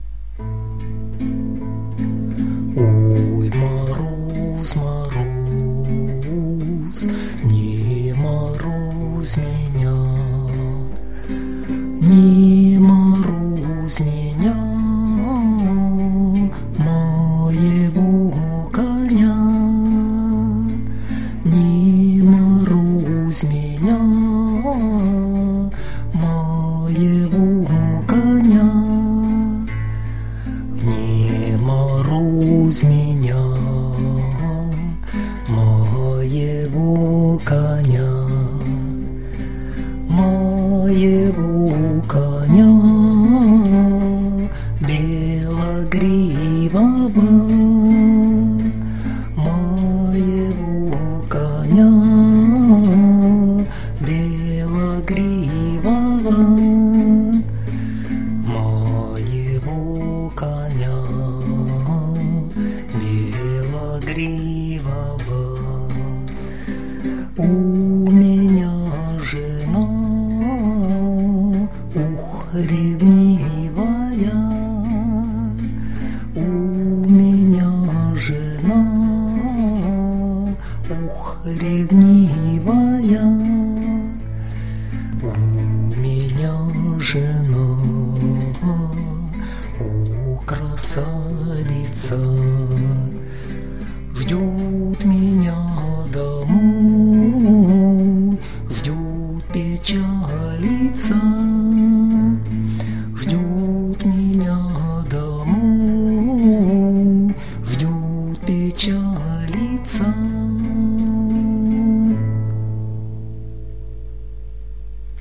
../icons/oypolnap.jpg   Русская народная песня